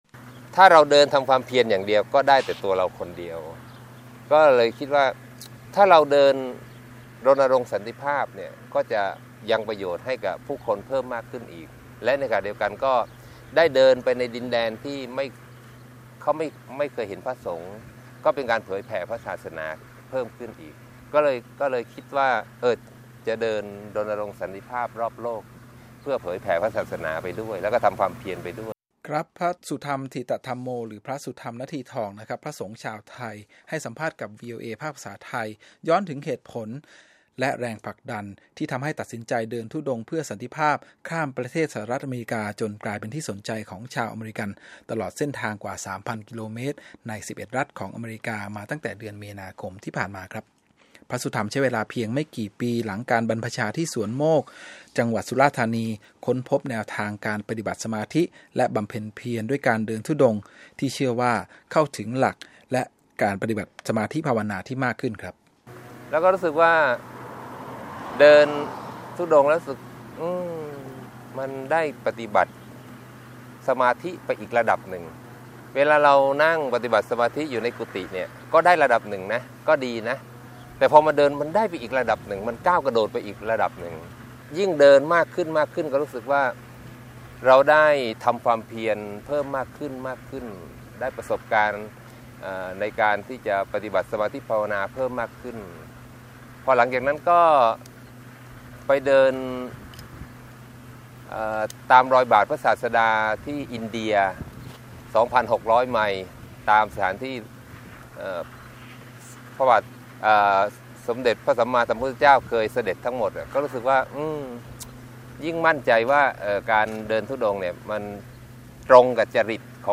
พระสุธรรม ฐิตธัมโม หรือ พระสุธรรม นทีทอง พระสงฆ์ชาวไทย ให้สัมภาษณ์กับ 'วีโอเอ ภาคภาษาไทย' ย้อนถึงเหตุผลและแรงผลักดันที่ทำให้ตัดสินใจ “เดินธุดงค์เพื่อสันติภาพ” ข้ามประเทศสหรัฐอเมริกา จนกลายเป็นที่สนใจของชาวอเมริกัน ตลอดเส้นทางกว่า 3 พันกิโลเมตร ใน 11 รัฐของอเมริกา มาตั้งแต่เดือนมีนาคม ที่ผ่านมา